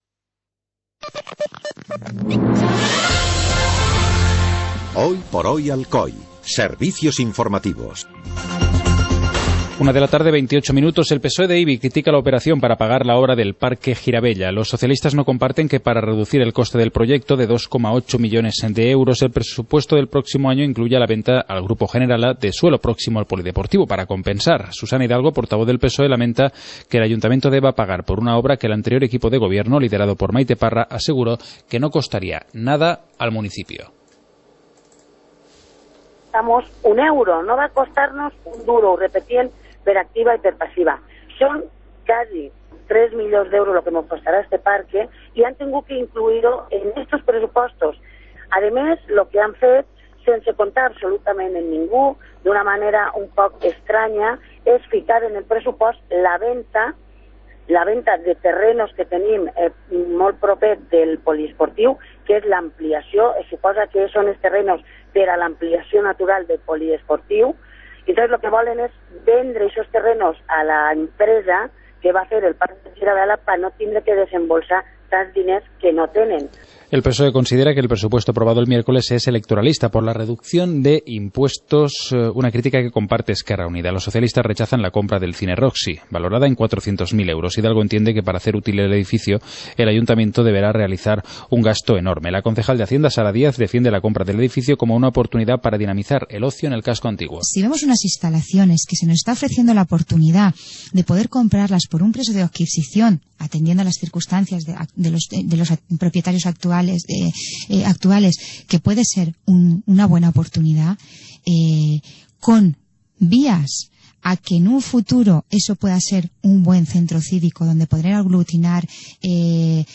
Informativo comarcal - viernes, 12 de diciembre de 2014